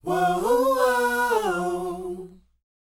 WHOA F#A.wav